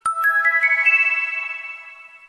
Play, download and share ring wand original sound button!!!!
ring-wand.mp3